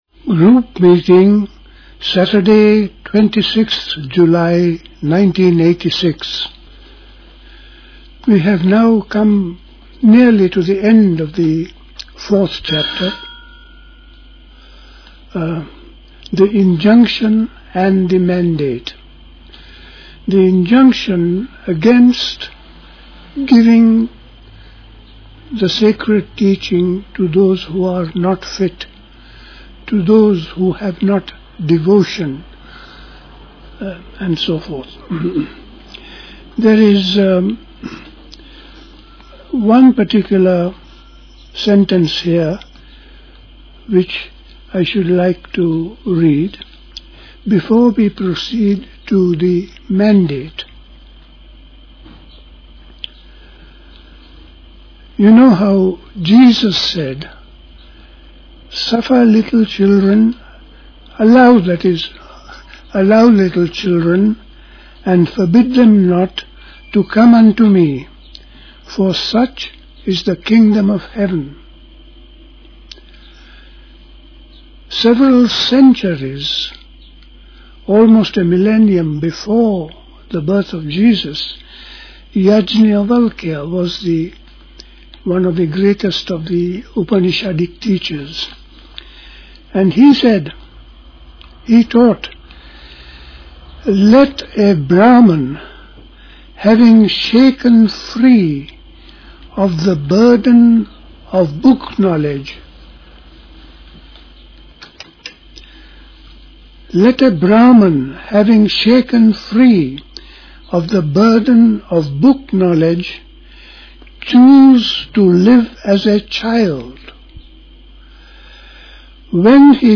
A talk
Dilkusha, Forest Hill, London